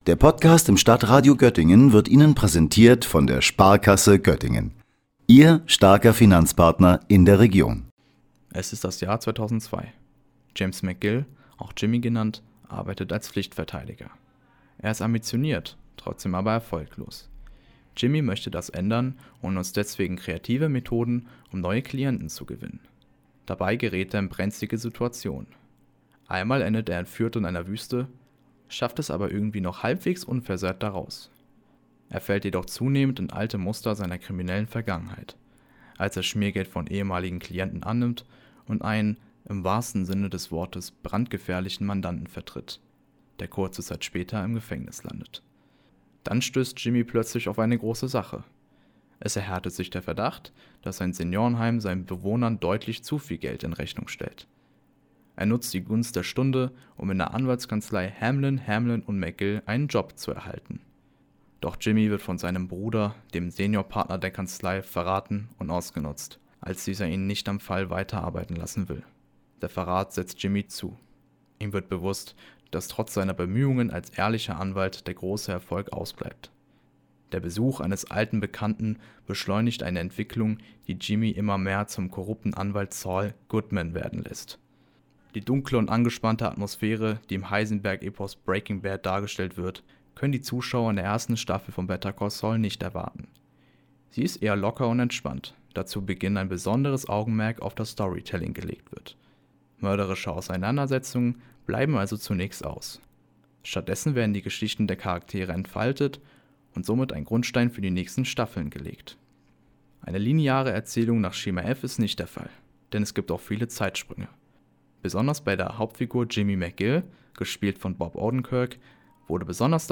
Beiträge > Rezension: „Better Call Saul“ - Ein Anwalt auf Abwegen - StadtRadio Göttingen